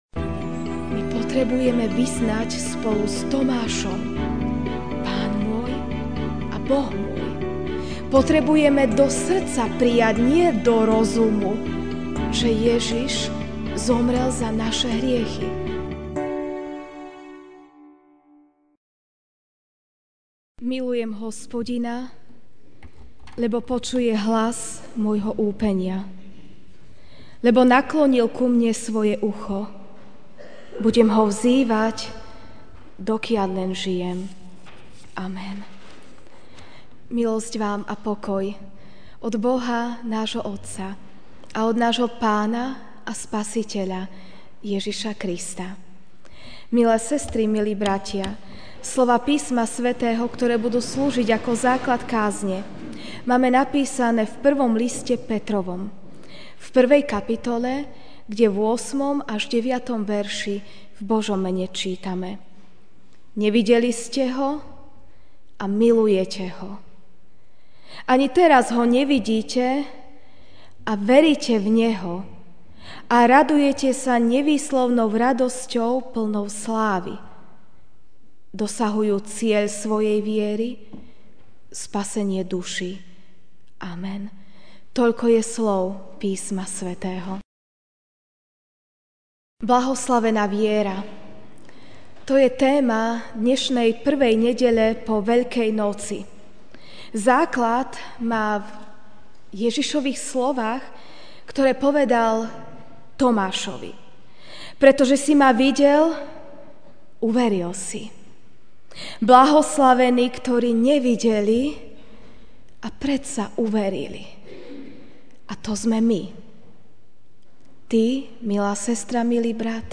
Ranná kázeň: Blahoslavená viera (1. Pt. 1, 8-9)